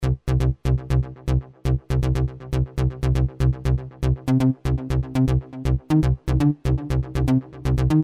Cube 滚动合成器低音
描述：滚动的恍惚的低音合成器声音
Tag: 130 bpm Techno Loops Bass Synth Loops 1.24 MB wav Key : F